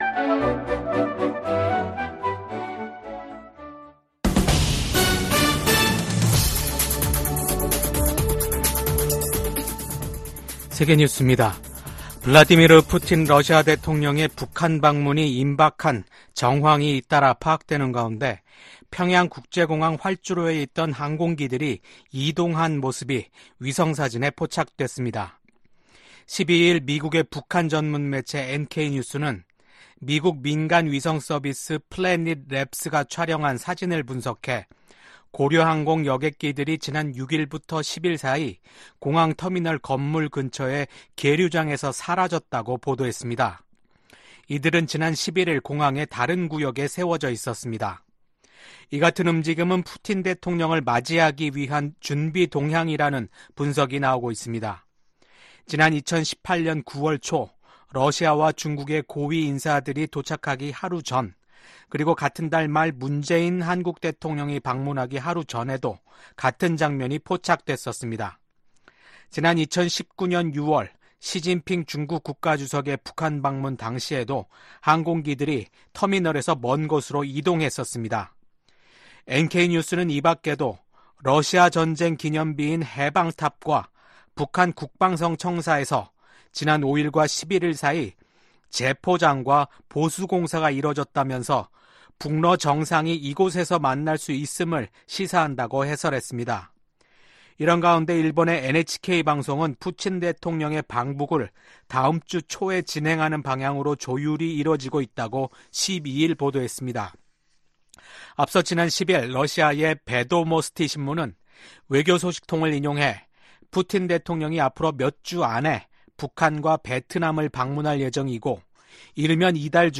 VOA 한국어 아침 뉴스 프로그램 '워싱턴 뉴스 광장' 2024년 6월 13일 방송입니다.